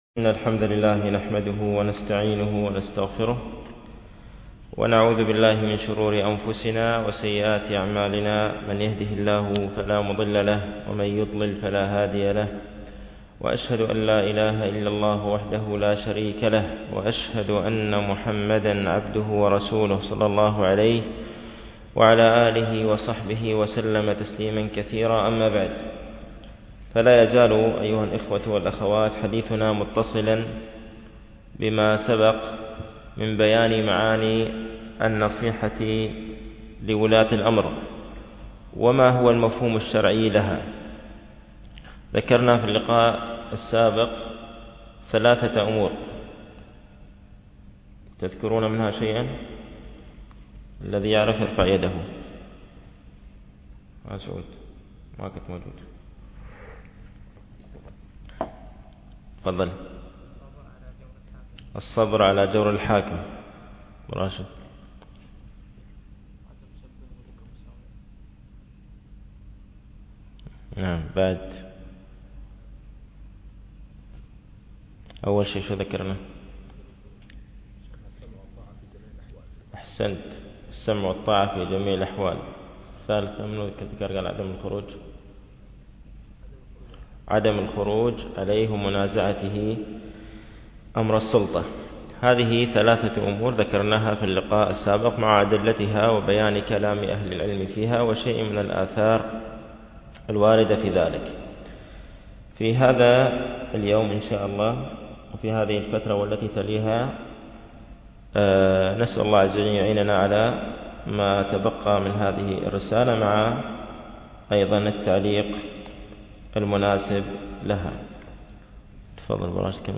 التنسيق: MP3 Mono 11kHz 32Kbps (CBR)